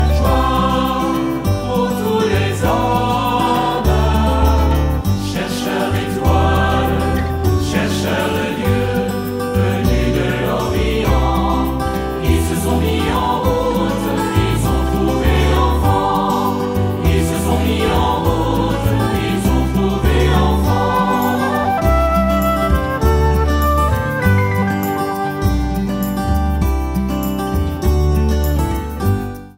Chant : Chercheurs d’étoiles
( F78-10, Hélène Perrin/Léandre Boldrini)
2CHERCHEURS-REFRAIN.mp3